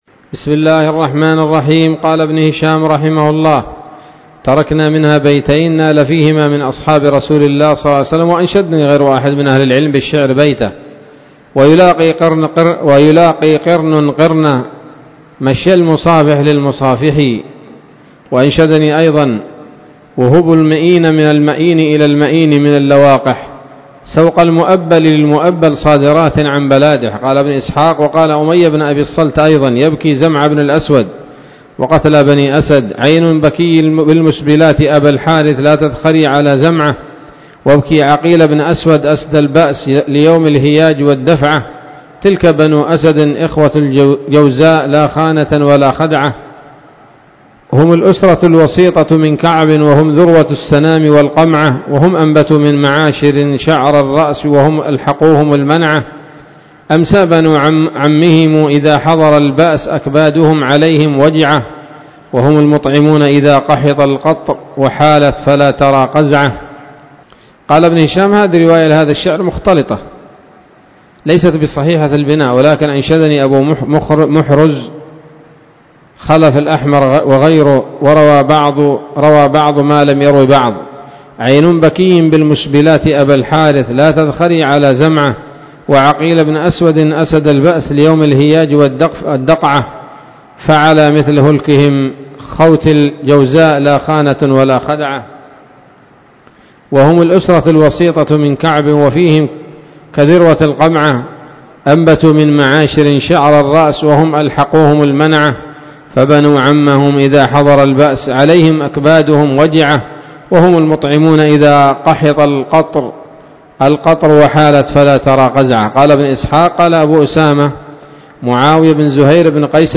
الدرس الخامس والأربعون بعد المائة من التعليق على كتاب السيرة النبوية لابن هشام